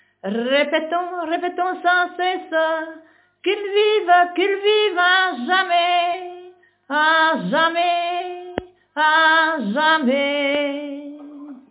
Genre : chant
Type : chanson narrative ou de divertissement
Aire culturelle d'origine : Fagne
Interprète(s) : Anonyme (femme)
Lieu d'enregistrement : Morville
Support : bande magnétique